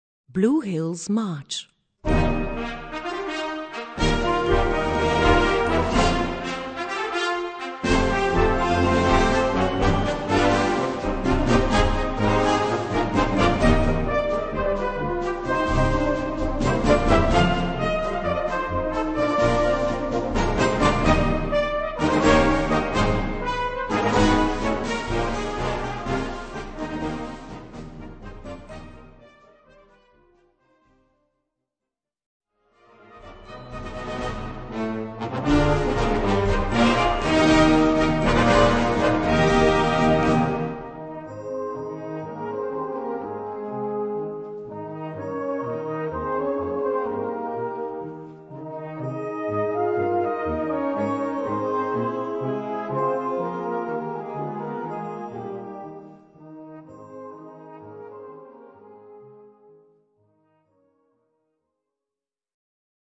Répertoire pour Harmonie/fanfare - Fanfare